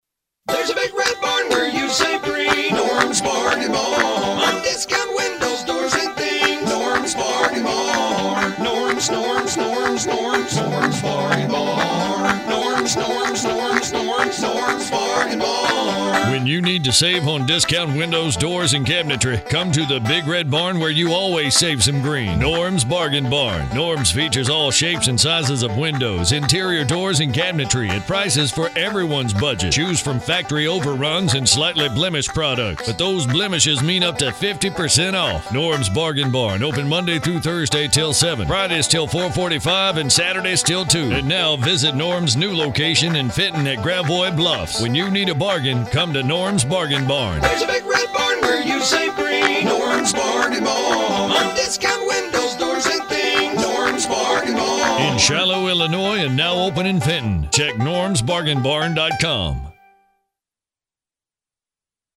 60 Second Banjo Spot:
NBB-Banjo_60.mp3